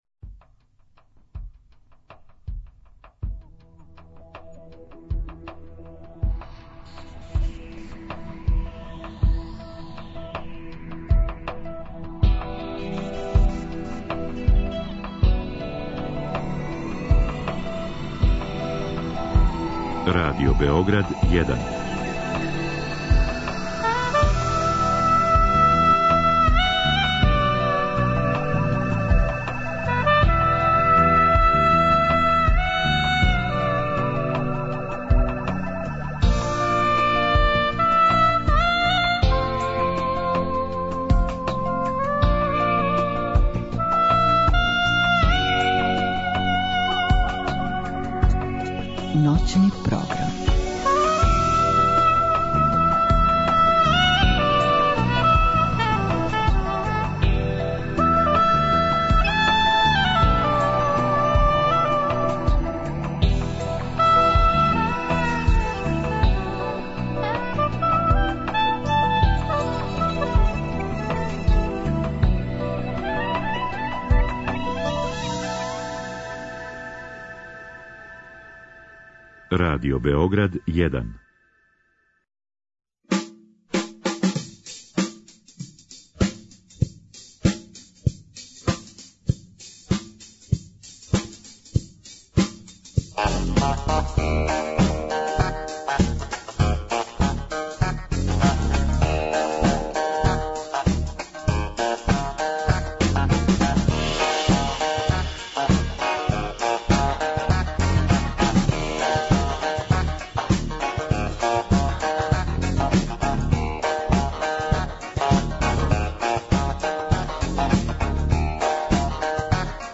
Специјално издање емисије – због ексклузивитета тема и гостију емисија уживо ће трајати до 03 сата.